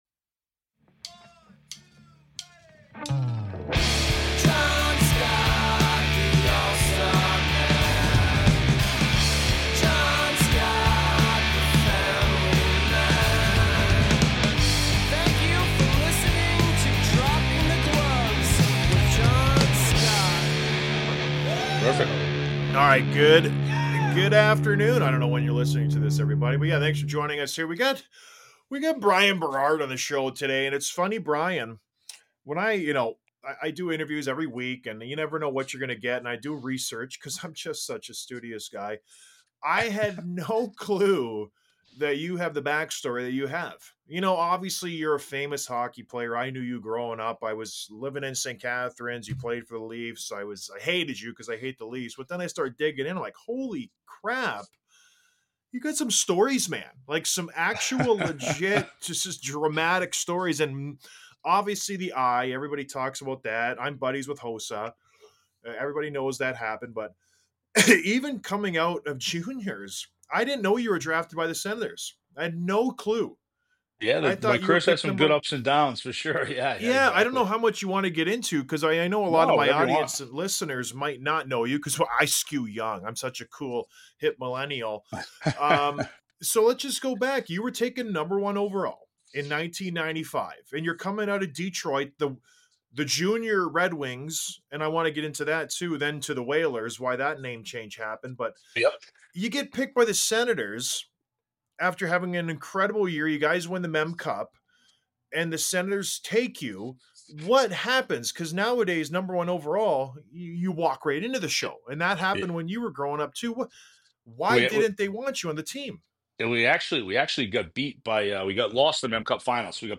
Had a great talk with Bryan about his career, which I had no clue how interesting it was.